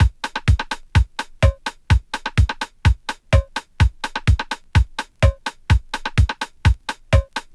I was warping a simple drum track I recorded from vinyl and there was so much squeaky artifact noise in it that I thought there must have been interference when I recorded it.
Original – not warped.